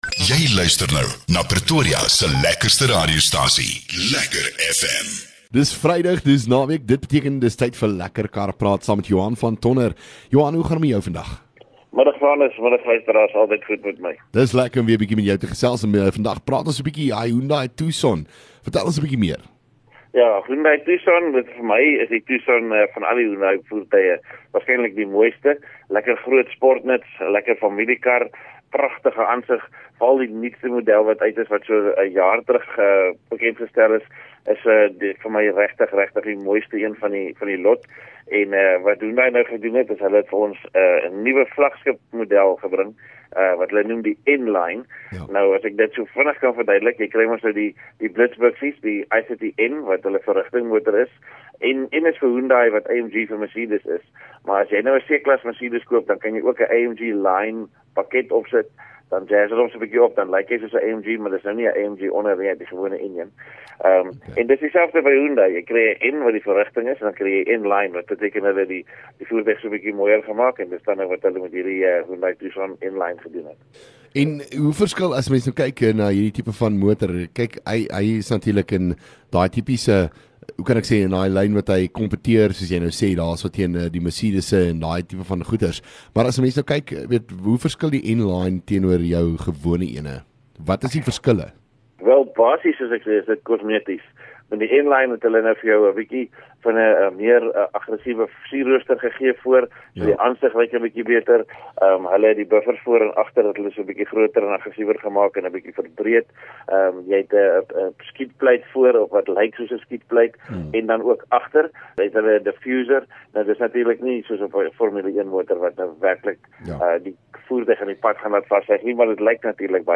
LEKKER FM | Onderhoude 9 Jun LEKKER Kar Praat